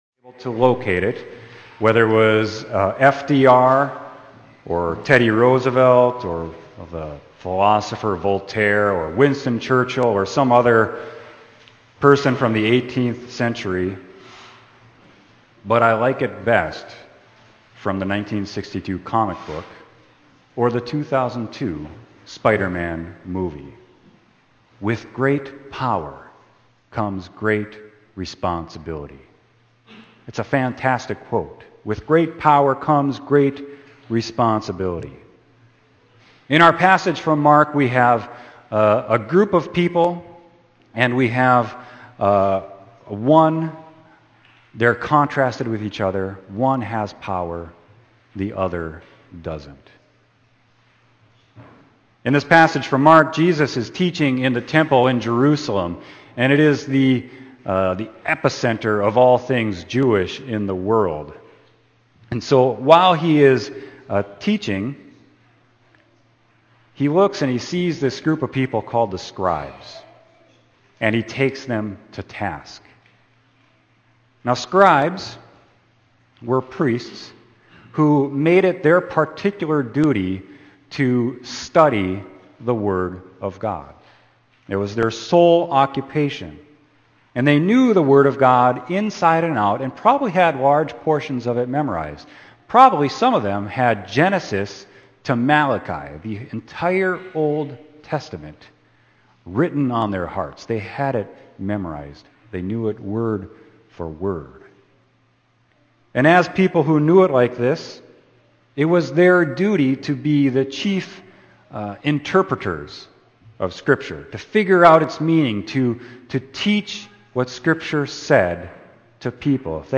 Sermon: Mark 12.38-44